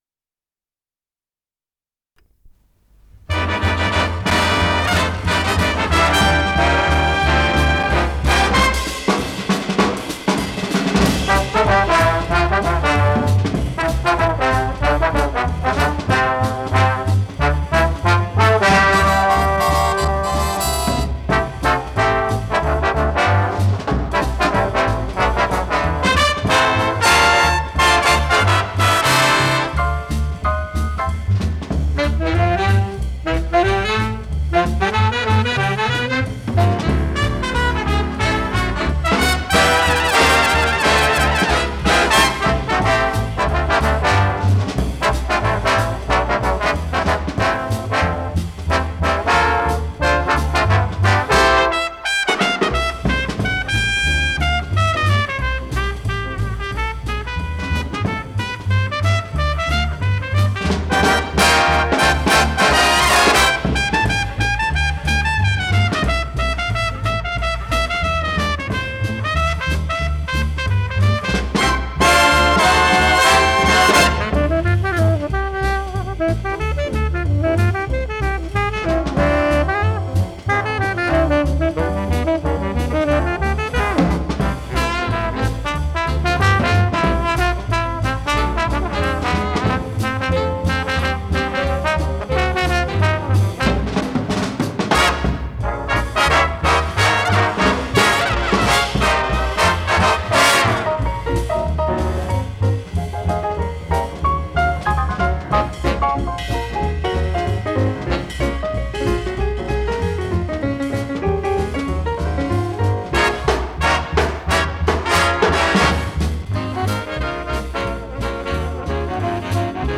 с профессиональной магнитной ленты
Скорость ленты38 см/с
Тип лентыШХЗ Тип 2
МагнитофонМЭЗ-109М